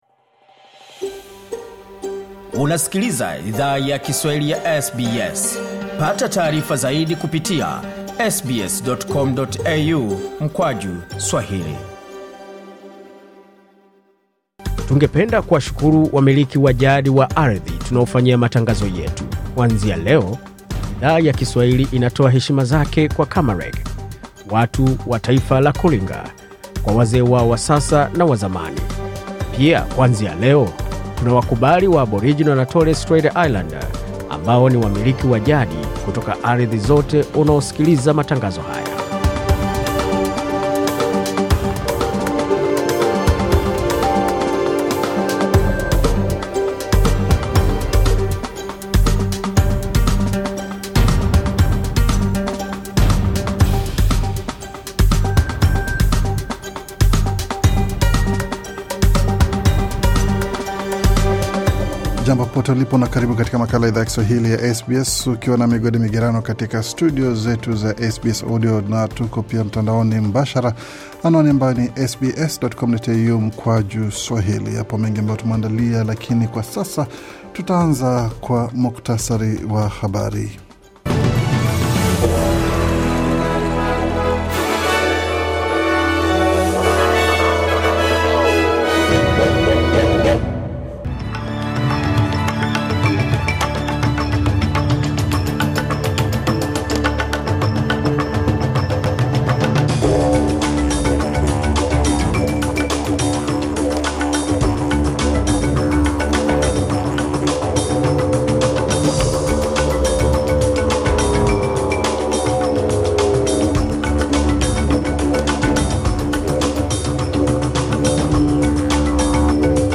Taarifa ya Habari 2 Mei 2025